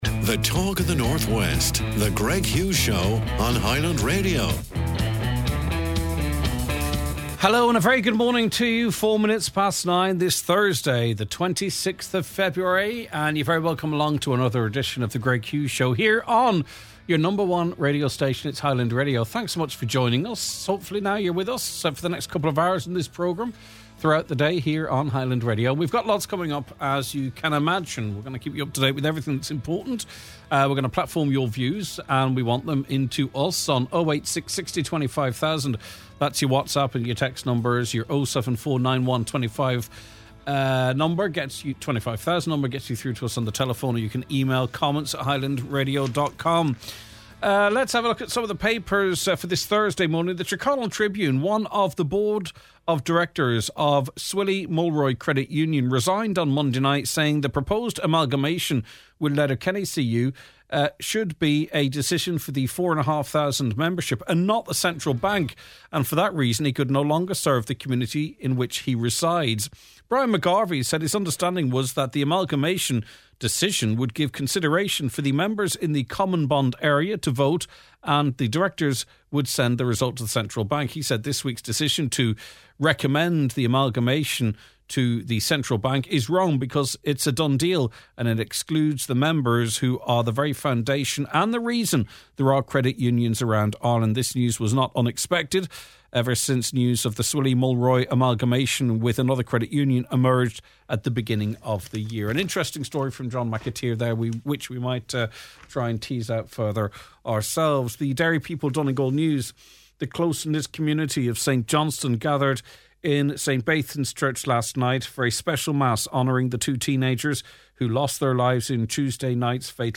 We speak with local campaigners who have spent years highlighting the dangers of local routes.